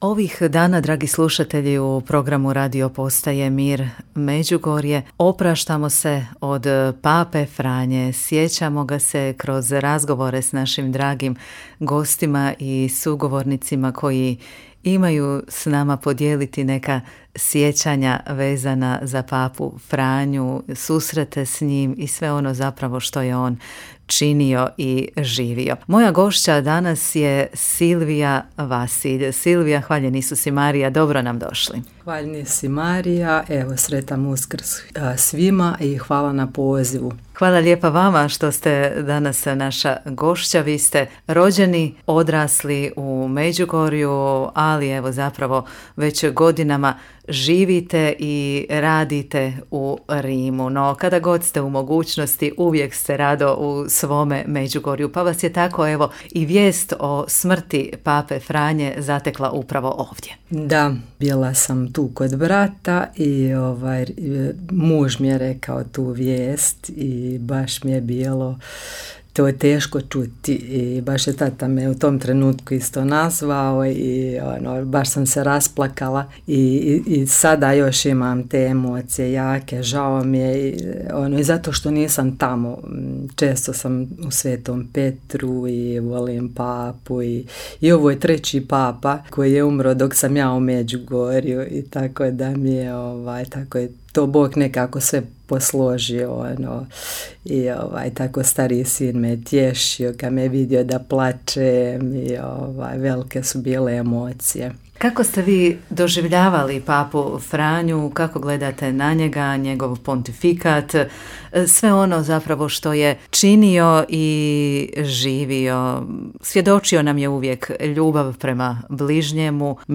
U razgovoru